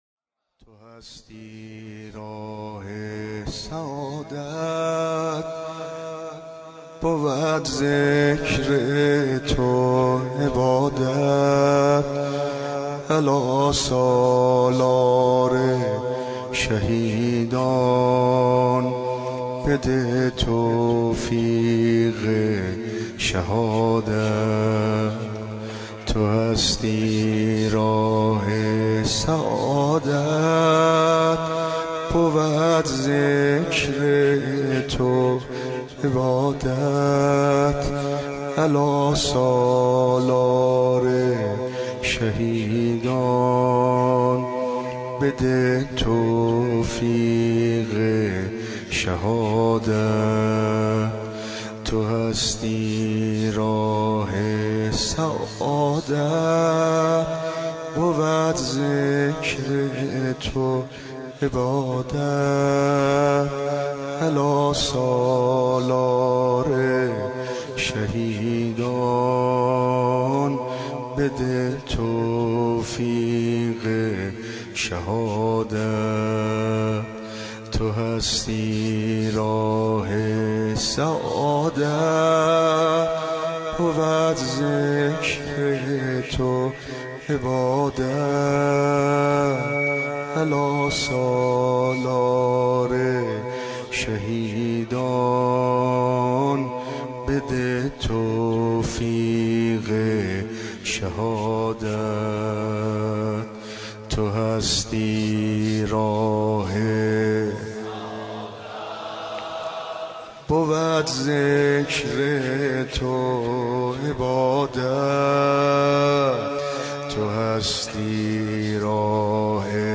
نوحه محرم
مراسم شب اول محرم 94